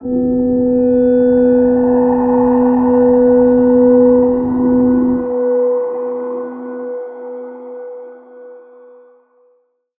G_Crystal-B4-mf.wav